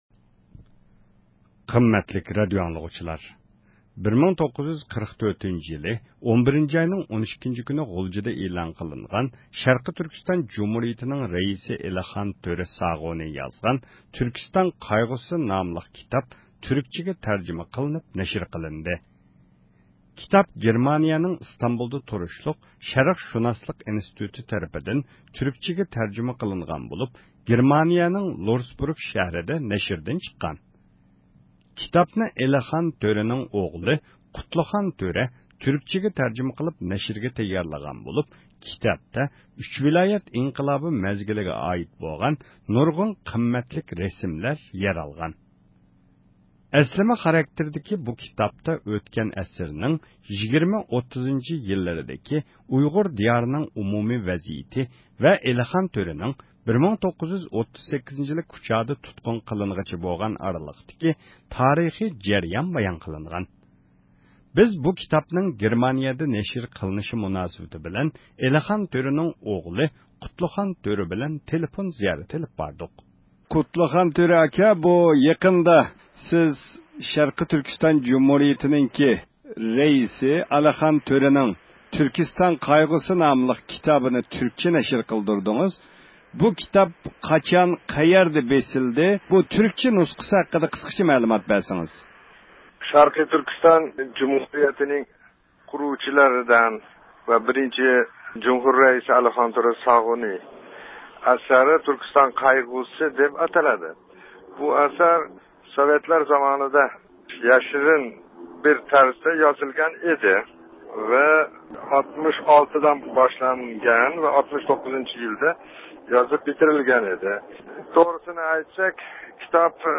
يۇقىرىدىكى ئۇلىنىشتىن، بۇ سۆھبەتنىڭ تەپسىلاتىنى سىلەرگە سۇنىمىز.